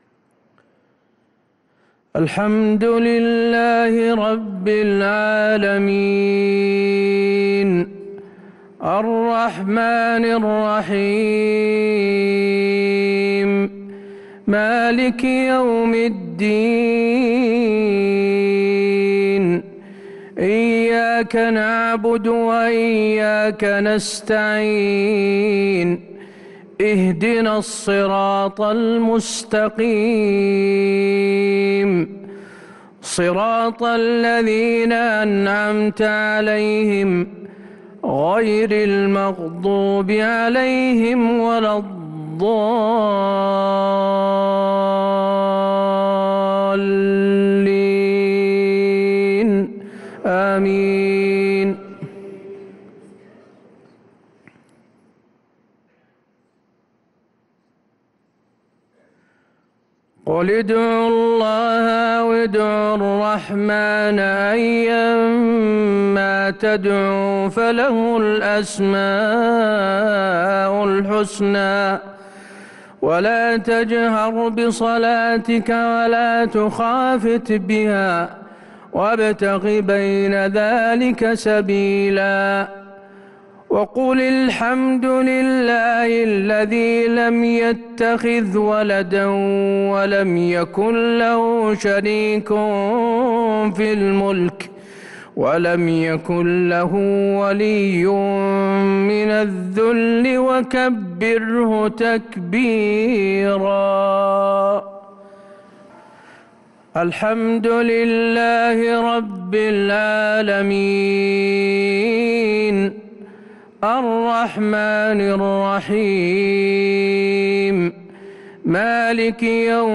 صلاة العشاء للقارئ حسين آل الشيخ 21 شوال 1443 هـ
تِلَاوَات الْحَرَمَيْن .